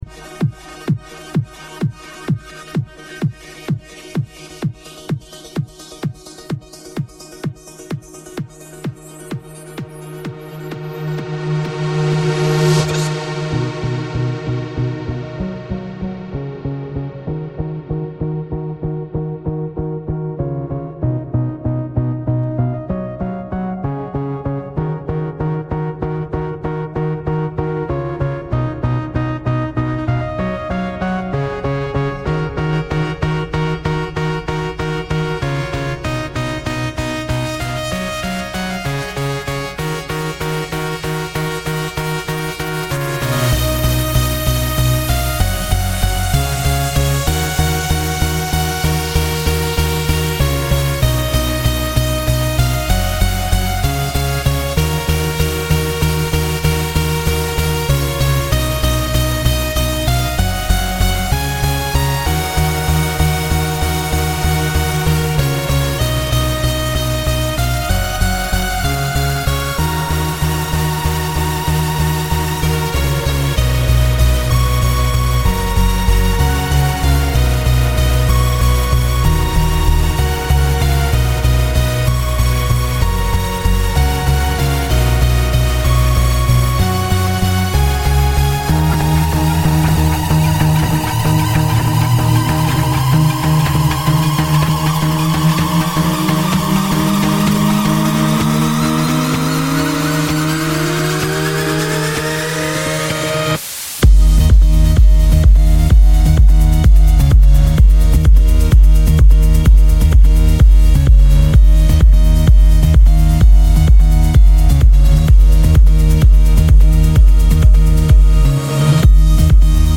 Electro Music
Dies ist ein Song, den ich mithilfe einer KI generiert habe.